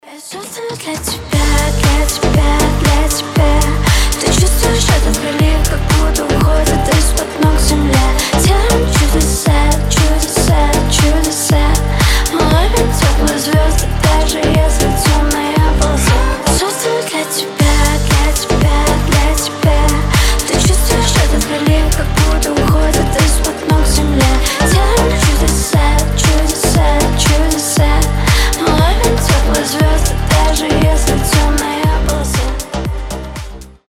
• Качество: 320, Stereo
чувственные
приятные
красивый женский голос
теплые